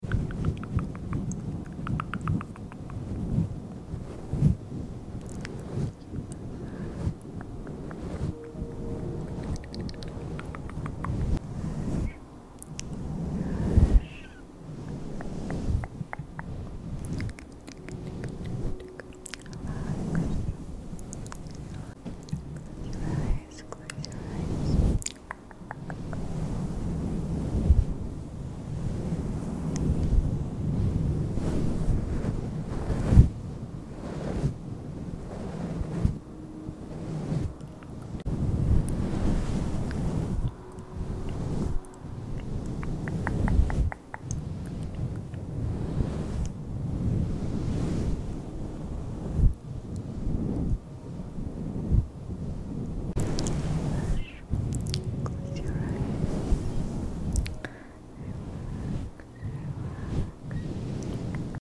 Personal attention for sleep ASMR-